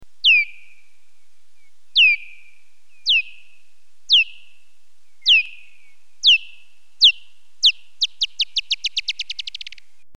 Noctule - Germany